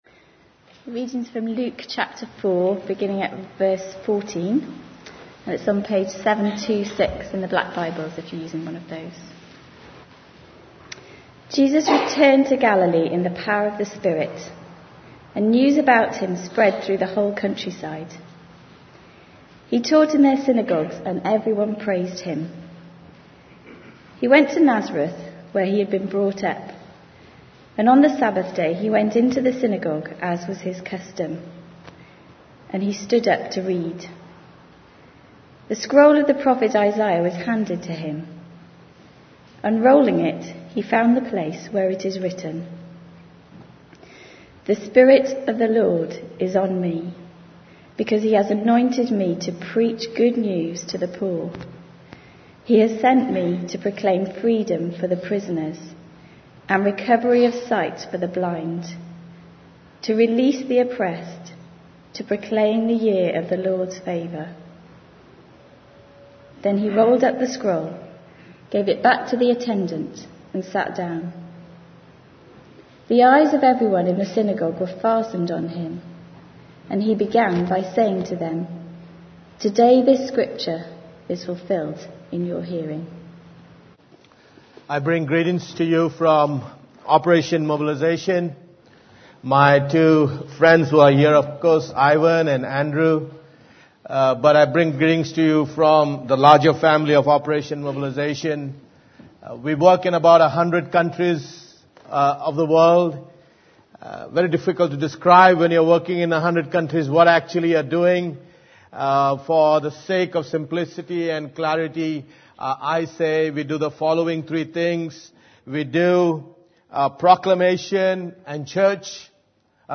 10 Oct - Morning Service - 09:30am
Sermon Title: Good News for the Poor - Luke 4:14-21